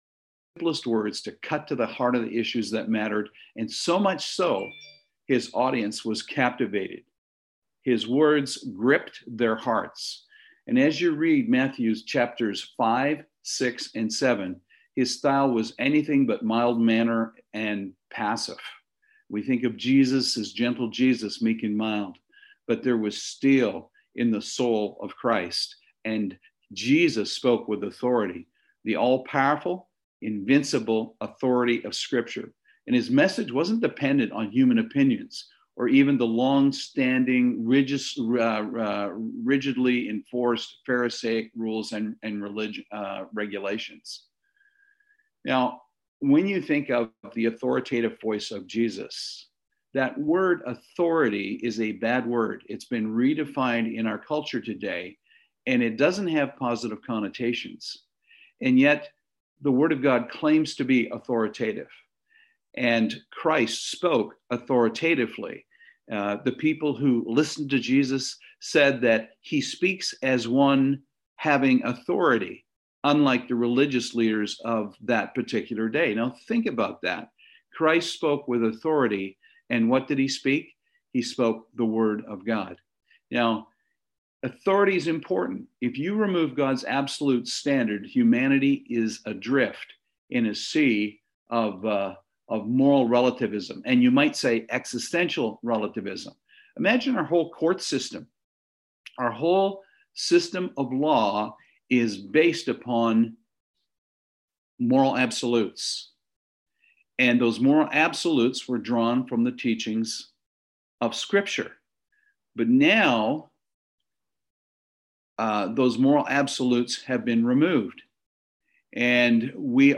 Sorry but the first 5 minutes of this sermon is missing due to a delay in recording.